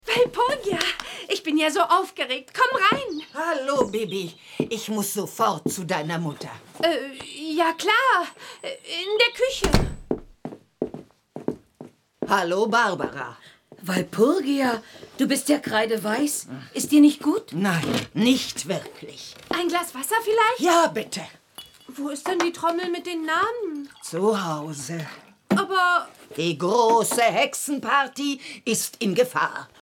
Ravensburger Bibi Blocksberg - Die große Hexenparty ✔ tiptoi® Hörbuch ab 4 Jahren ✔ Jetzt online herunterladen!